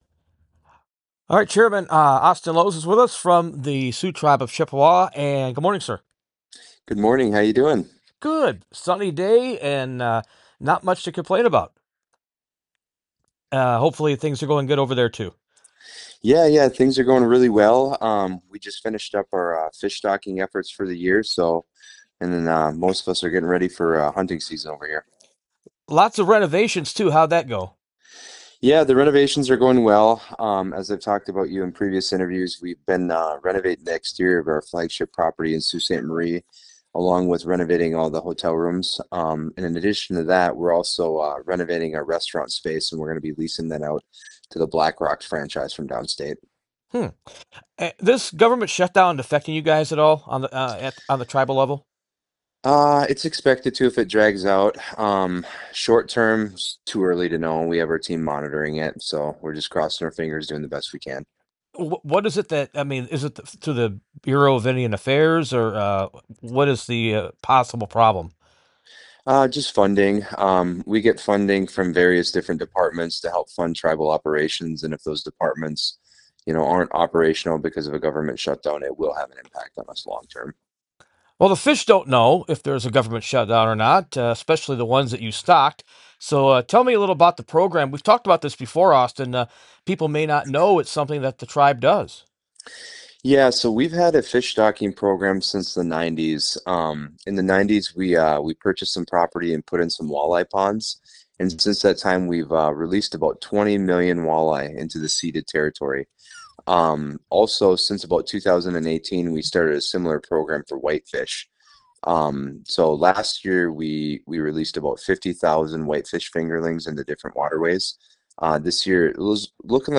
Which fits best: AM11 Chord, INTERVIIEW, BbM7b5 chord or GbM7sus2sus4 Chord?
INTERVIIEW